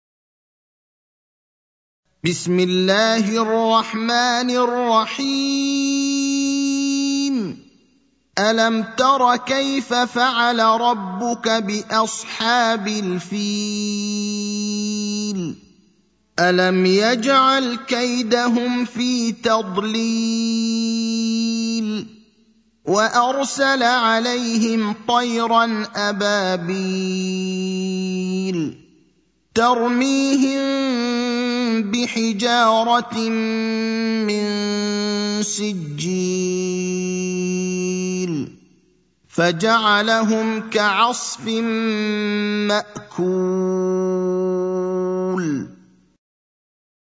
المكان: المسجد النبوي الشيخ: فضيلة الشيخ إبراهيم الأخضر فضيلة الشيخ إبراهيم الأخضر الفيل (105)